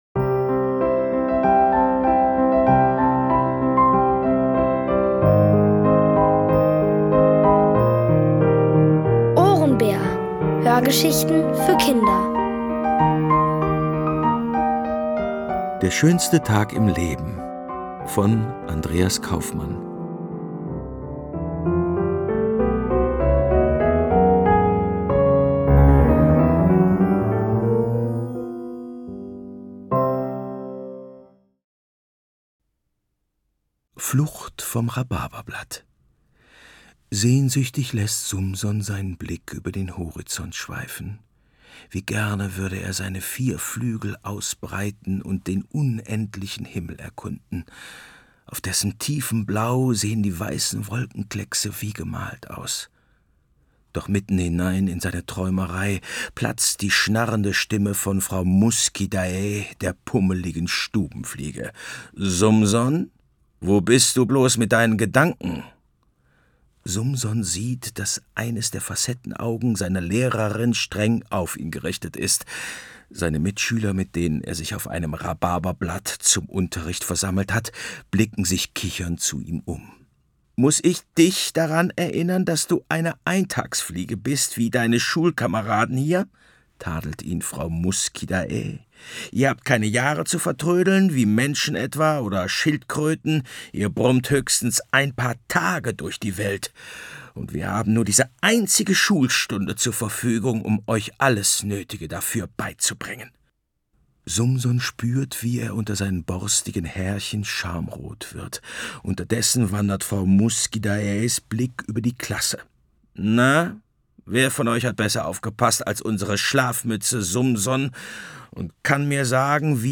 Von Autoren extra für die Reihe geschrieben und von bekannten Schauspielern gelesen.
Es liest: Bernhard Schütz.